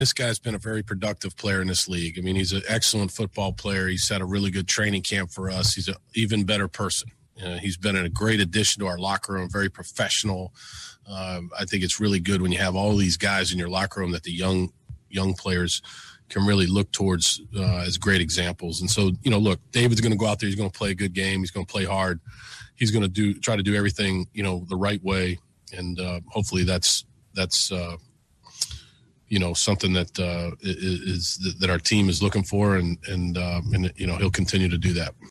Head coach Bill O’Brien spoke about the addition of David Johnson: